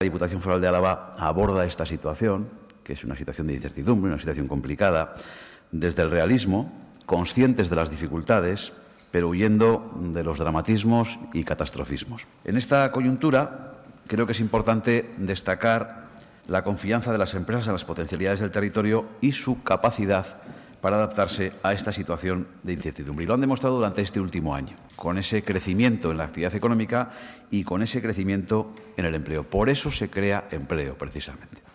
En la sesión del pleno de control de Juntas Generales, el diputado general, ha manifestado que hay que dejar de lado los catastrofismos y repartir las cargas para afrontar la crisis.